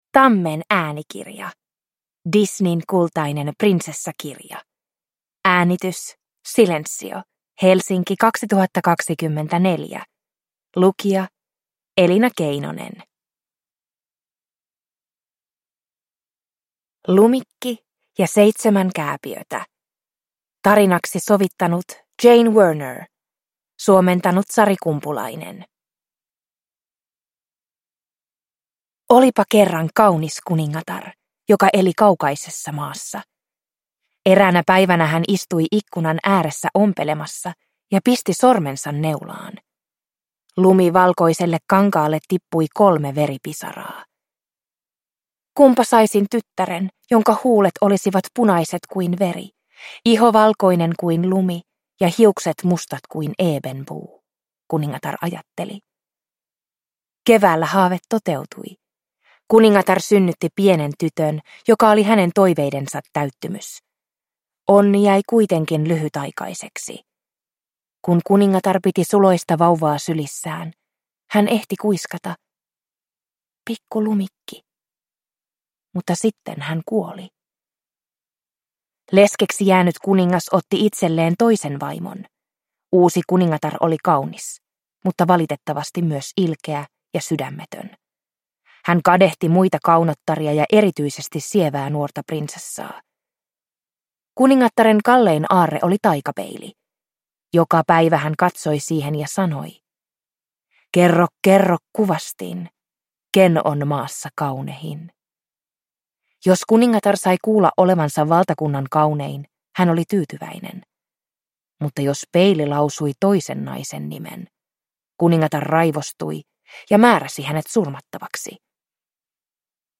Disneyn kultainen prinsessakirja – Ljudbok